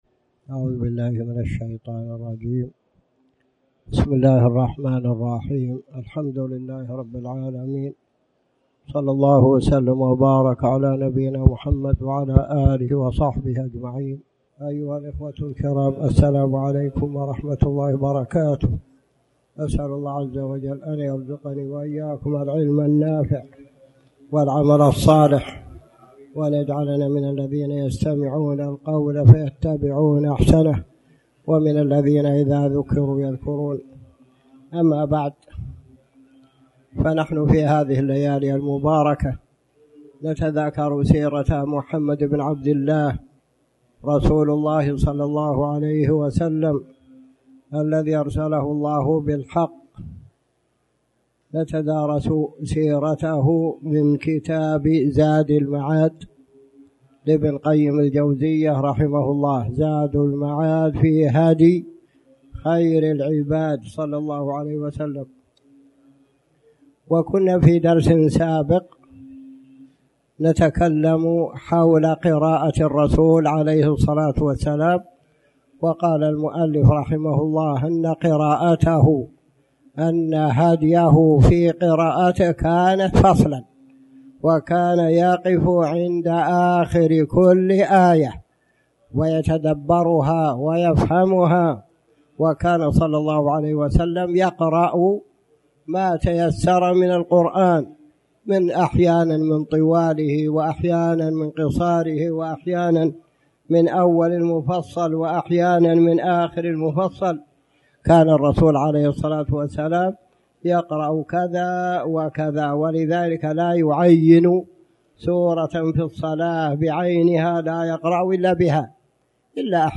تاريخ النشر ٢٨ رجب ١٤٣٩ هـ المكان: المسجد الحرام الشيخ